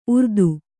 ♪ urdu